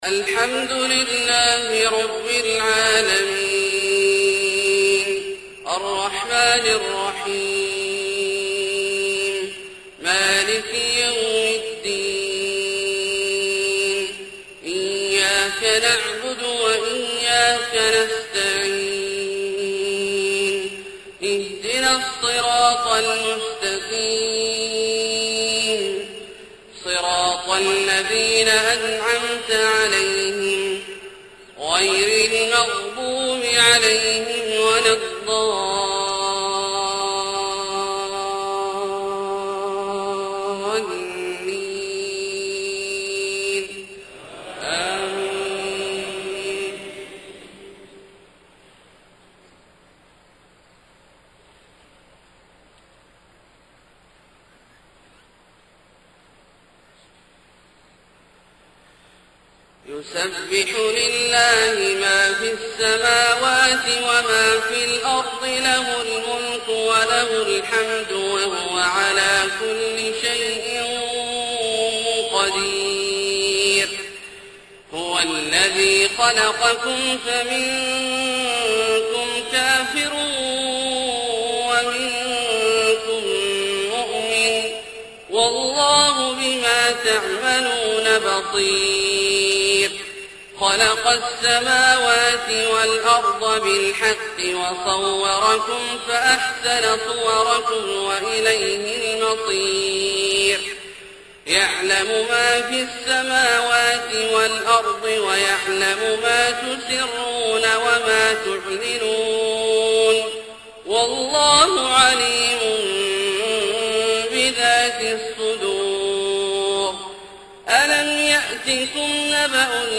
صلاة الفجر9-8-1429 سورة التغابن > ١٤٢٩ هـ > الفروض - تلاوات عبدالله الجهني